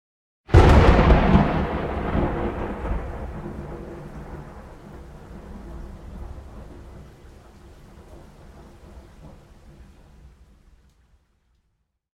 HauntedBloodlines/Thunder Strike.wav at main
Thunder Strike.wav